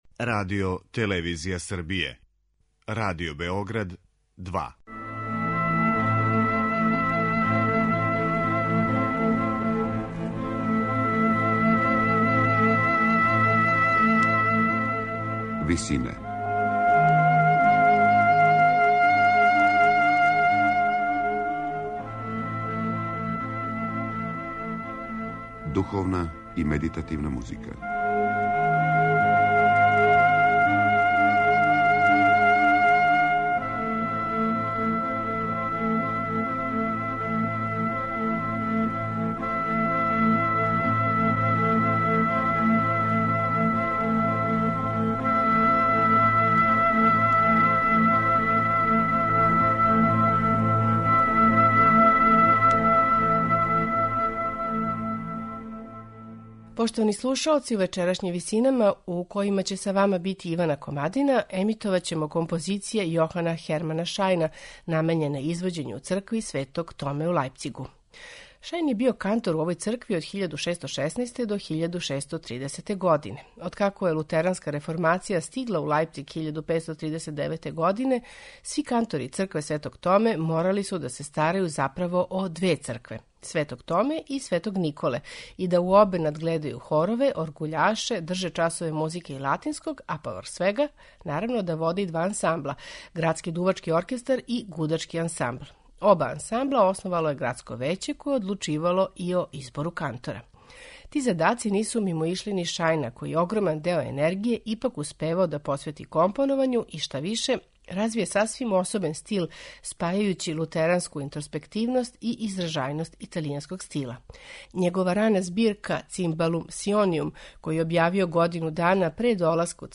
У вечерашњим Висинама , слушаћемо Шајнове композиције писане за извођење у цркви Светог Томе: канцоне, свите и обраде протестанских корала.
сопрани
тенор и ансамбл Иналто .
медитативне и духовне композиције